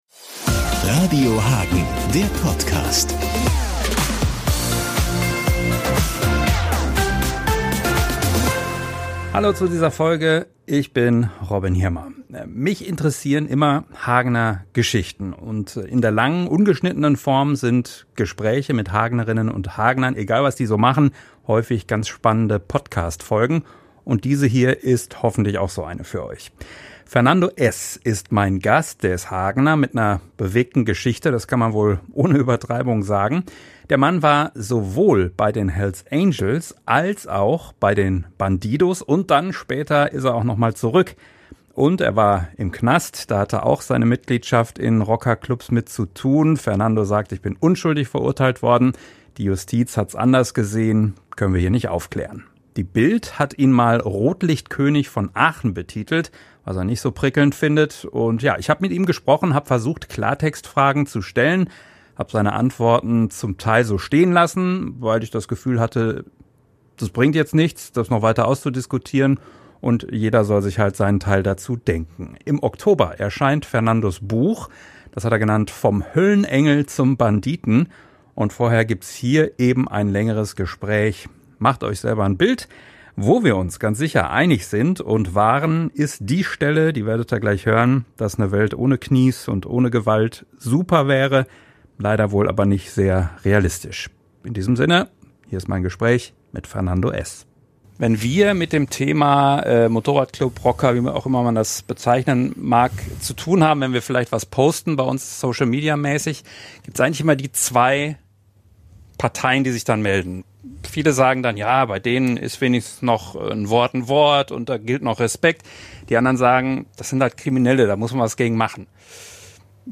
Hier ist das ausführliche Interview, um sich selber ein Bild zu machen.